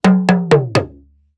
African Talking Drum FIll 1 Percussion Loop (87BPM).wav